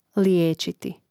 lijéčiti liječiti